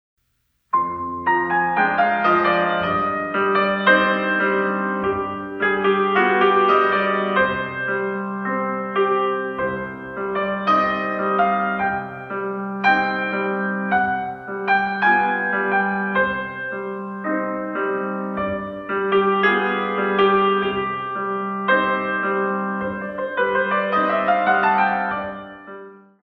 In 2
64 Counts